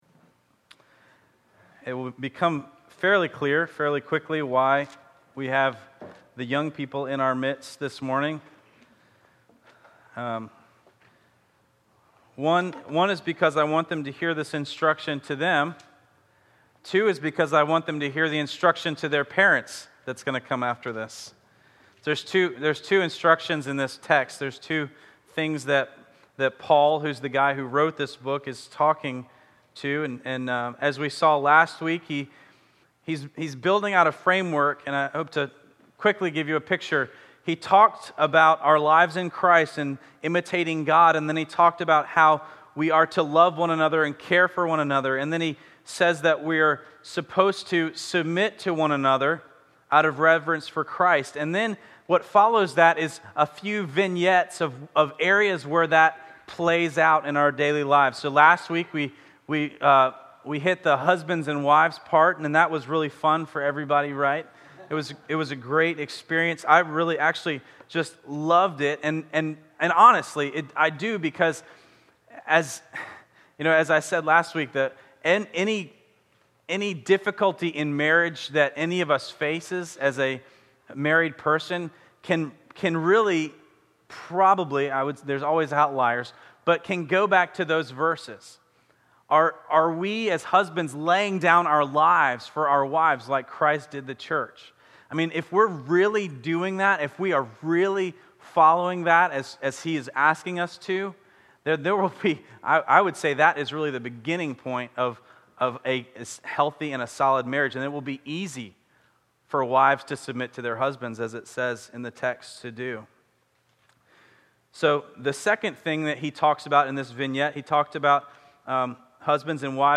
ephesians 6-1-4 Children and parents First off, I want to give a shout out to all the young people who are in this portion of the service.